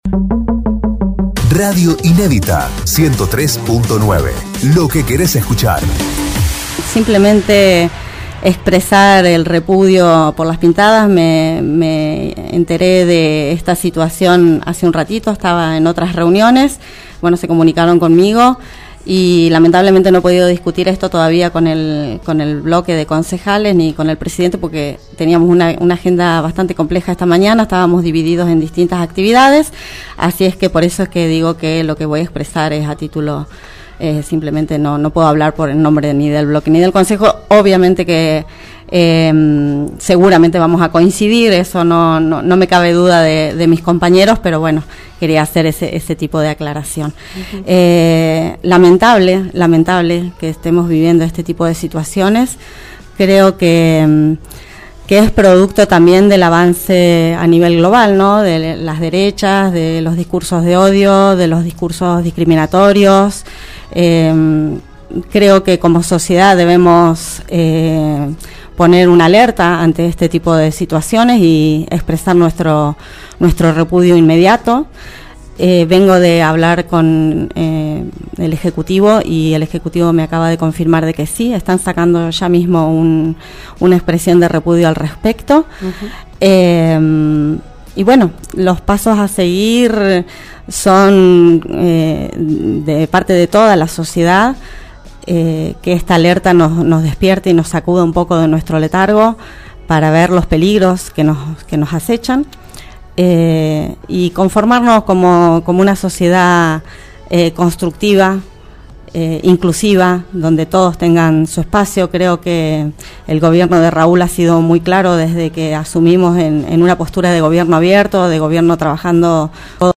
En Agenda (L a V 9hs) la Concejal de Cosquín Avanza Liliana Emerson se expresó al respecto